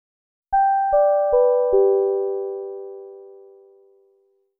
chimedown.wav